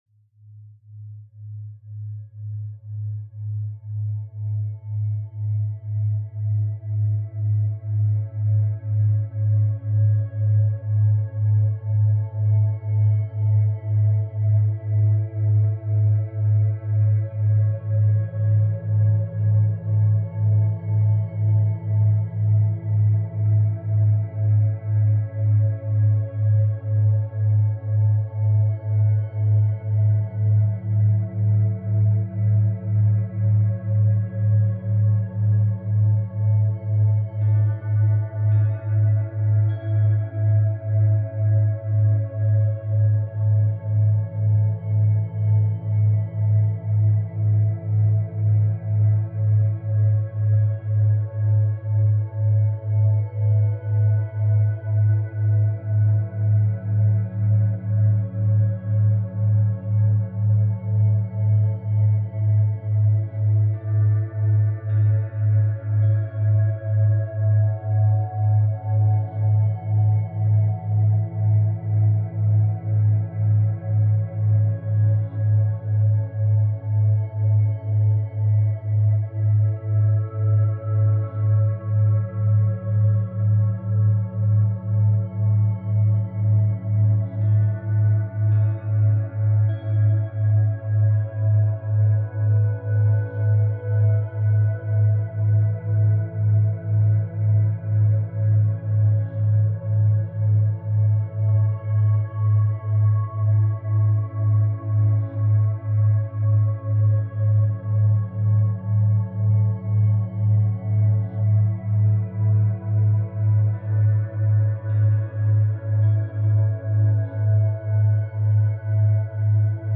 Study Sounds, Background Sounds, Programming Soundscapes, Coding Beats Quantum Healing – Total Body, Mind & Emotional Recovery May 11 2025 | 01:00:00 1x 00:00 / 01:00:00 Subscribe Share RSS Feed Share Link Embed